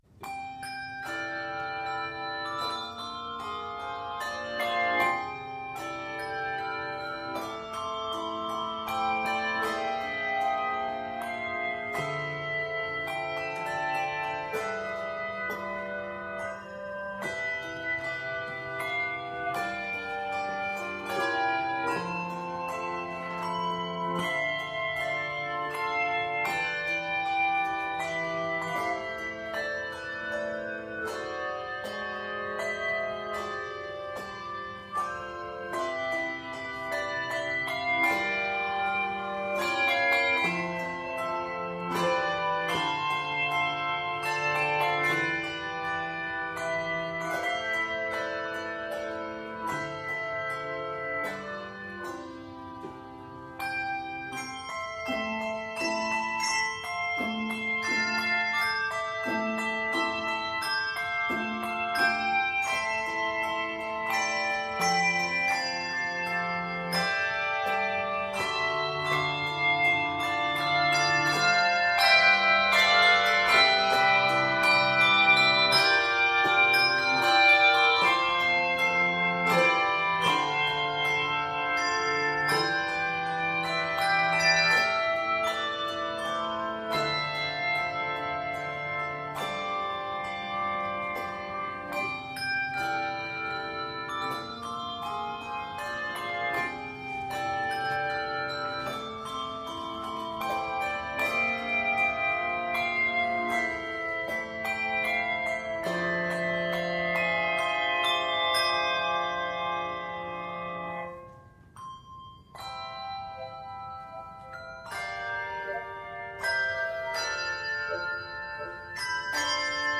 This 19th-century hymn centers on the theme of prayer
3-5 octave handbells